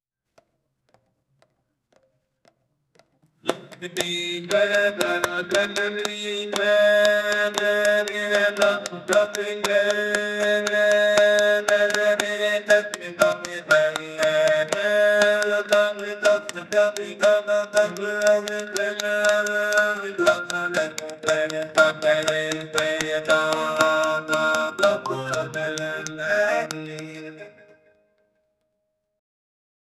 Acapella Sound Effects - Free AI Generator & Downloads
create-cinematic-fast-rhy-j2t2tukq.wav